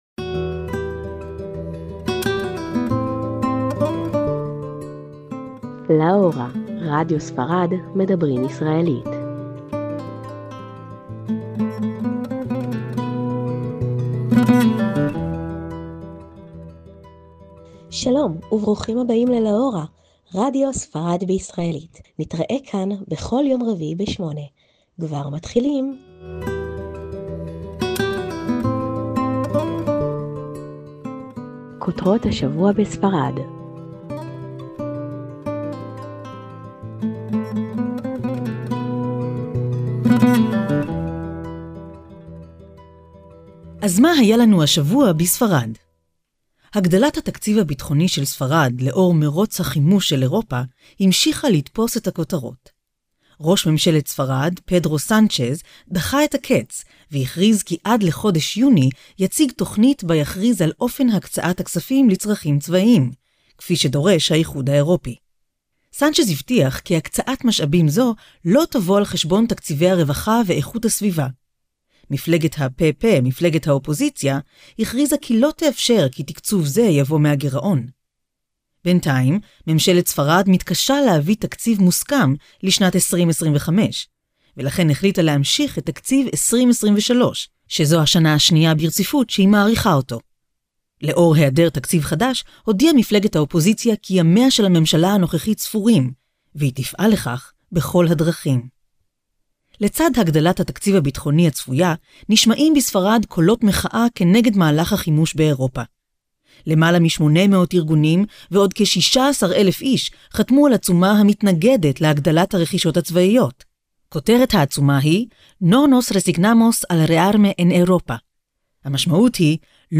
תכנית רדיו בעברית לטובת הישראלים בספרד